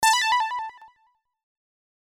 Cat Pickup Effect
This was a "pickup" effect I had made for an older game prototype to try and mimic a cat "meow", but with more of a chiptune style.
Art Type: Sound Effect
catpickup.mp3